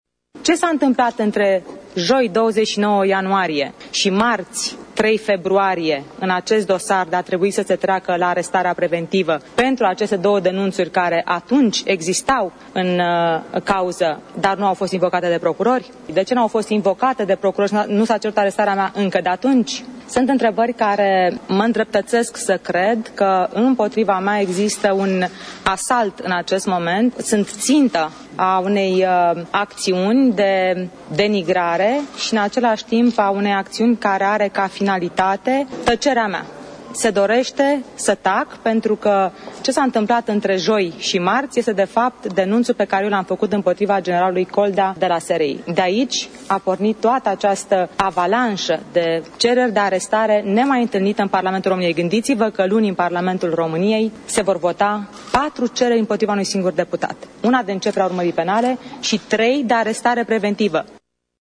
Elena Udrea a mai subliniat că nu înţelege de ce procurorii DNA au cerut încuviinţarea arestării sale preventive: